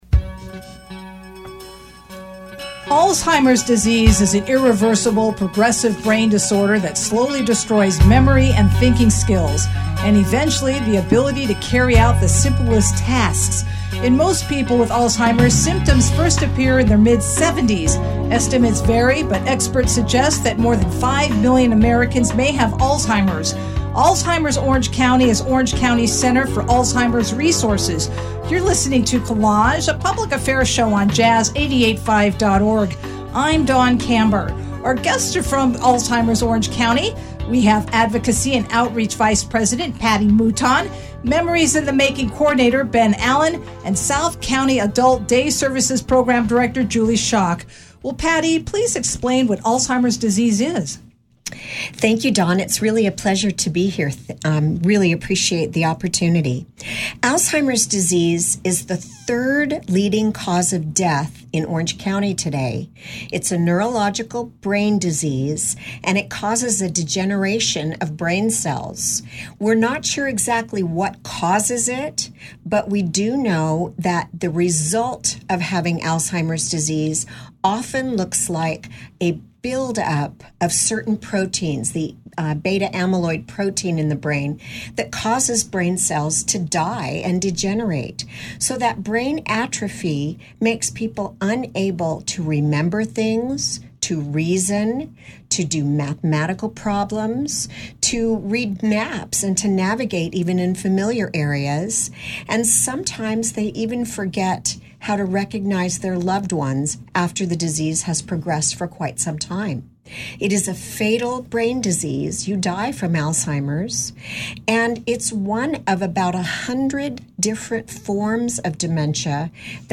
Alzheimer's Orange County representatives make an appearance on a recent broadcast of the KSBR News show.